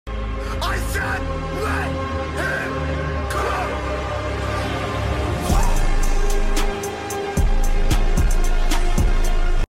Ultimate Cybertruck Transition sound effects free download